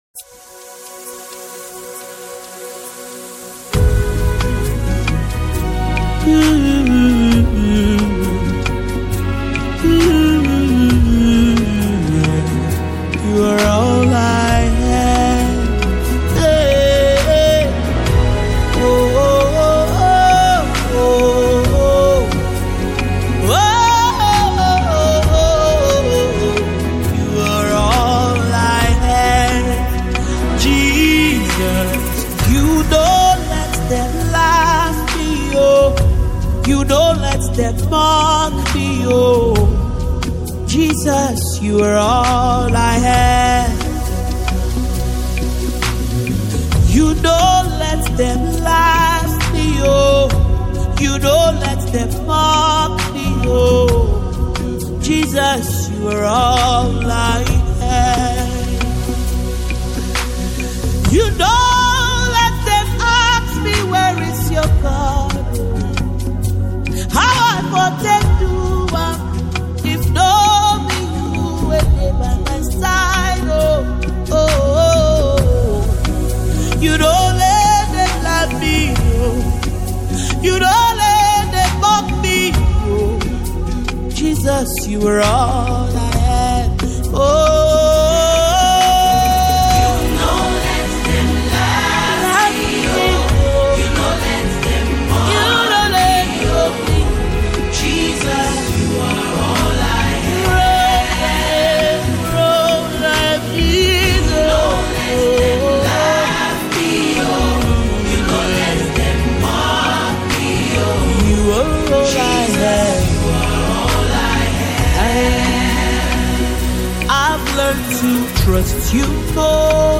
powerful gospel anthem